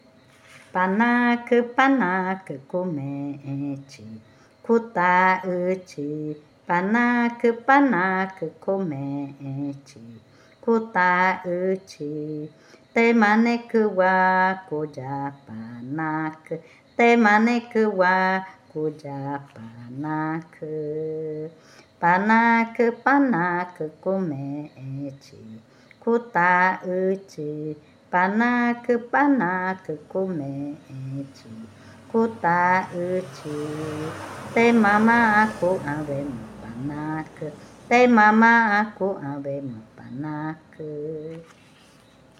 Canción infantil 14. Canción de la sachavaca
Cushillococha